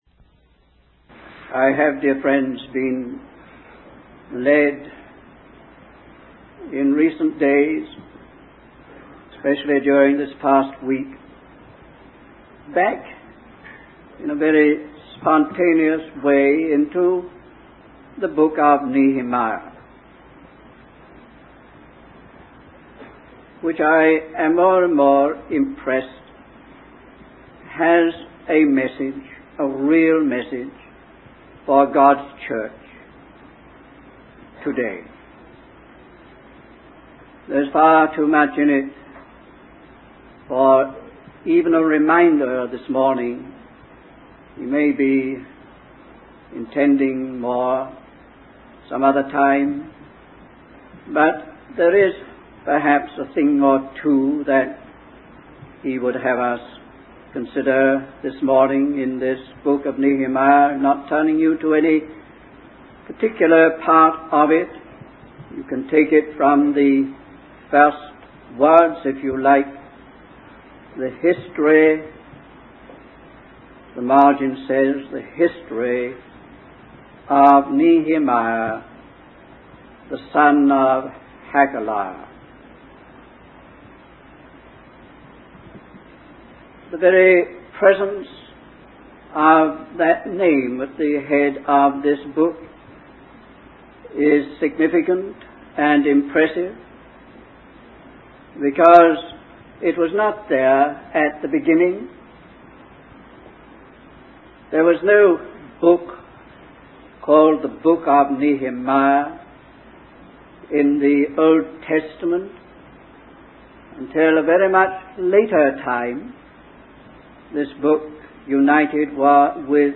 In this sermon, the speaker emphasizes that God has a purpose in our time and our lives. He highlights the importance of serving God's purpose and being governed by it. The speaker uses the example of the completion of the wall in the book of Nehemiah to illustrate how God never undertakes something he cannot see through.